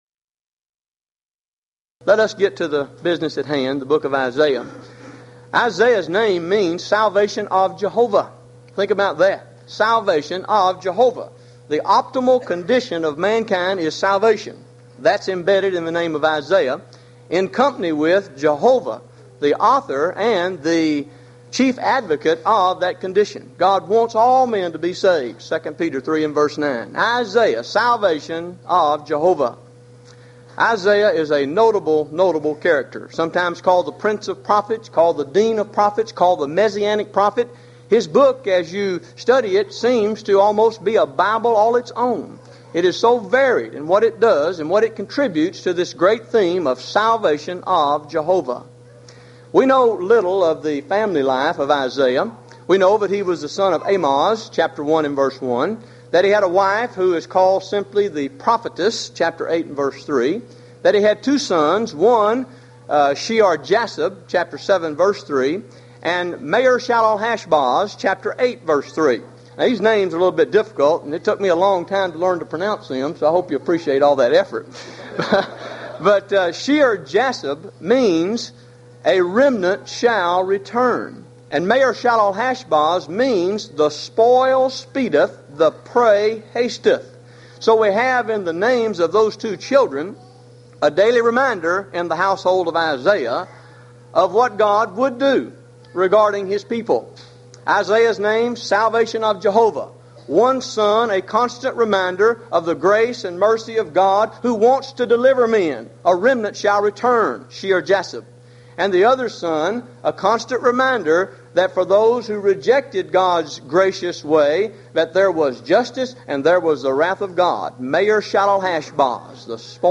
Event: 1995 HCB Lectures
lecture